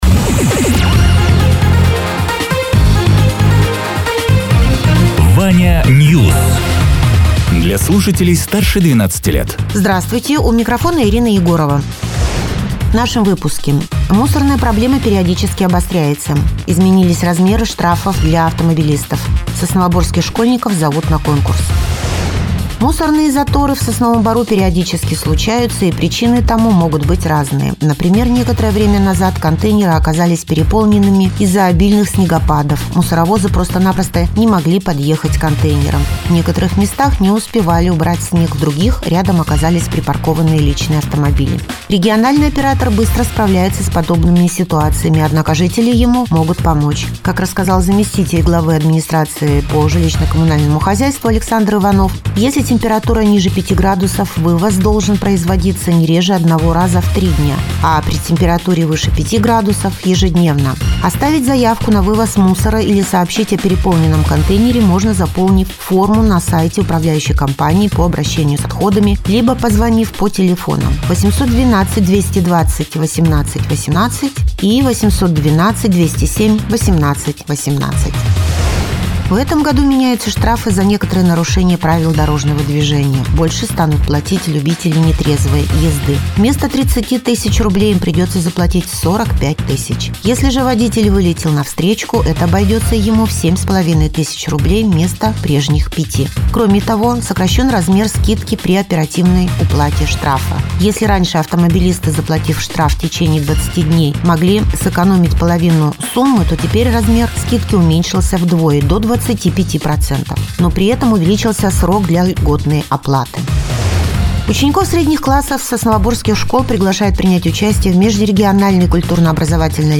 Радио ТЕРА 22.01.2025_12.00_Новости_Соснового_Бора